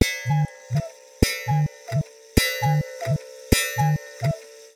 Cuckoo_clock.ogg